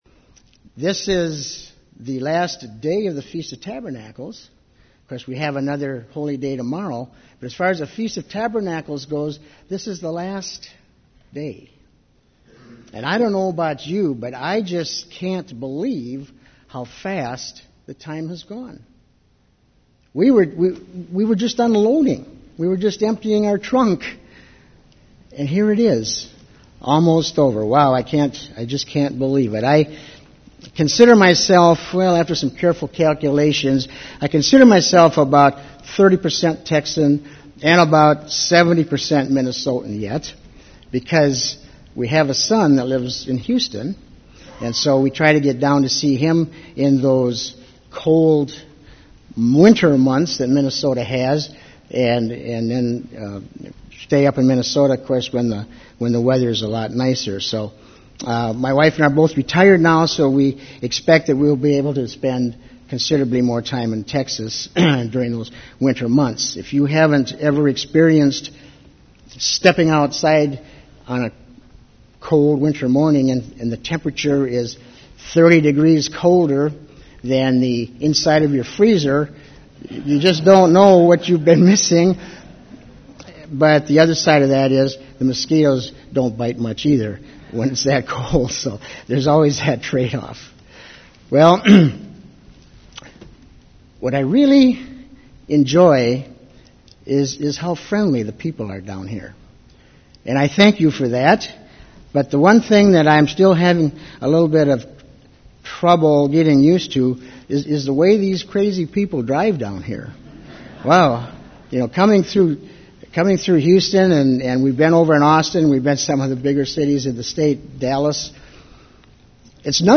This sermon was given at the New Braunfels, Texas 2012 Feast site.